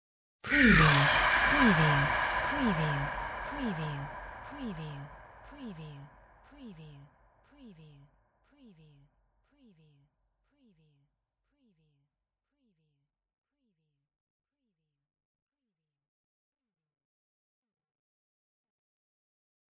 Surreal whisper EEYA 03
Stereo sound effect - Wav.16 bit/44.1 KHz and Mp3 128 Kbps
previewSCIFI_WHISPERS_SPOOKY_EEYA_WBHD03.mp3